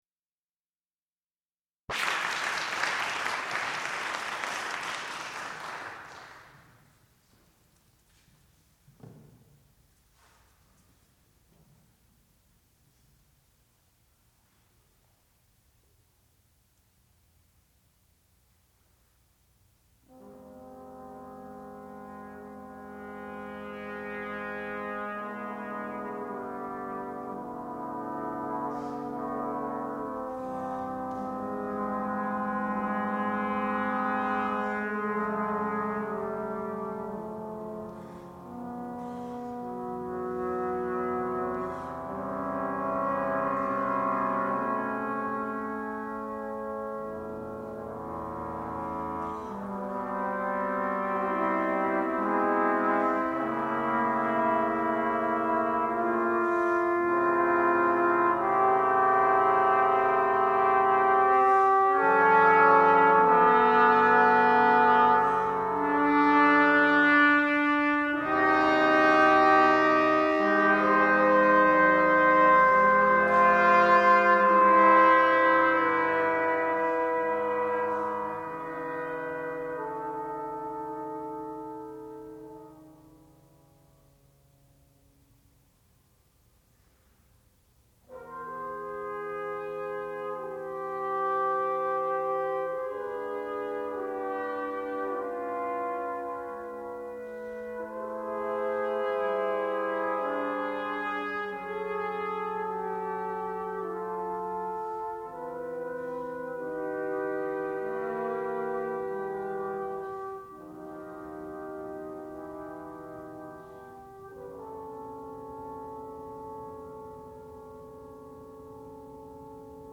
sound recording-musical
classical music
trombone